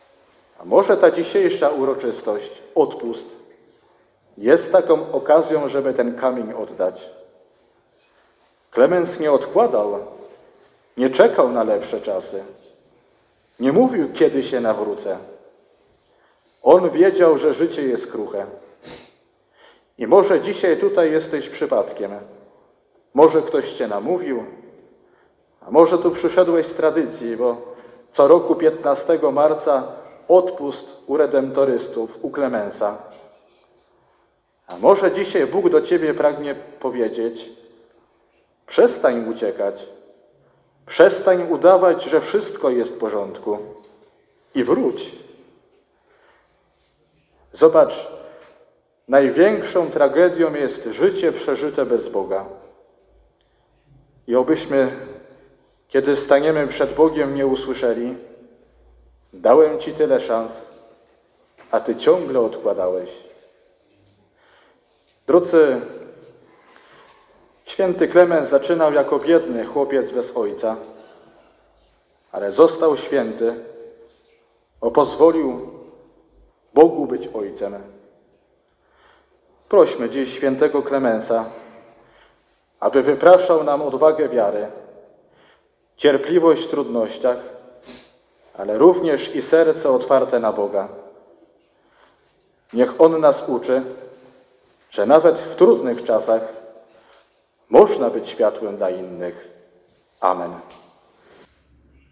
fragmenty homilii audio: